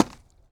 Bubberstation/sound/effects/treechop/treechop3.ogg
treechop3.ogg